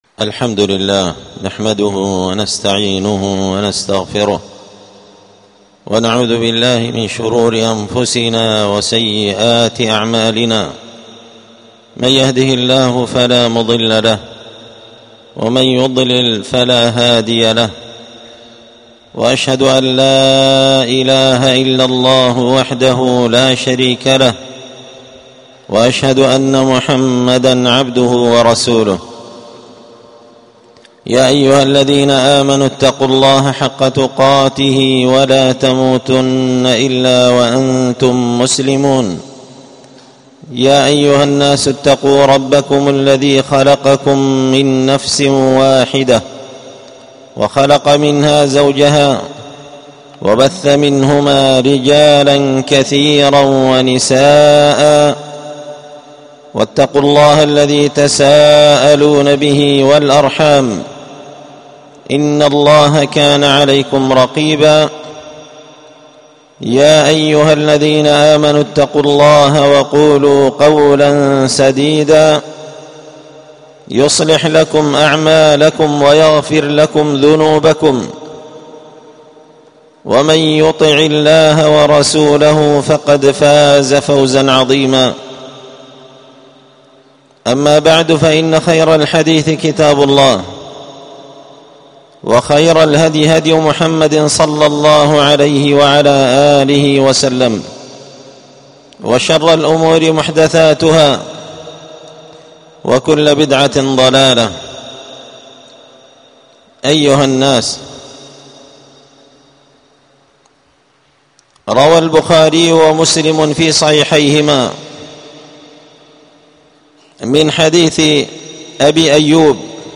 ألقيت هذه الخطبة بدار الحديث السلفية بمسجد الفرقان